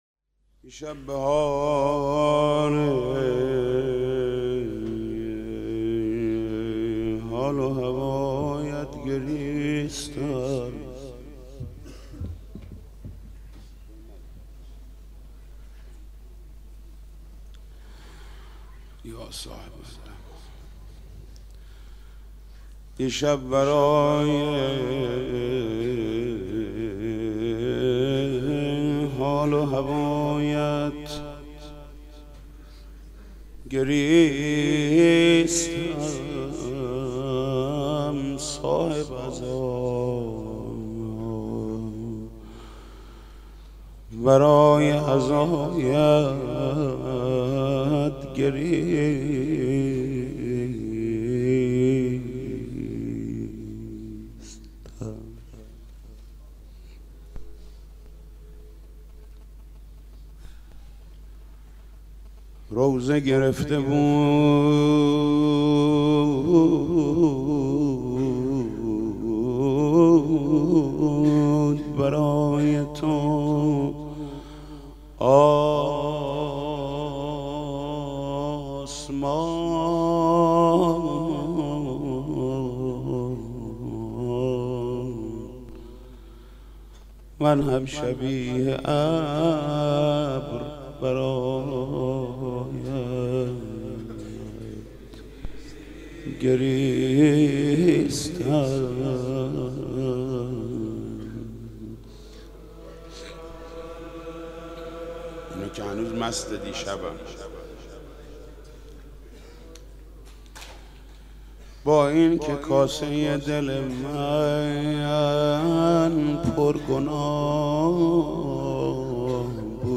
مداحی و نوحه
سینه زنی در شهادت حضرت فاطمه زهرا(س)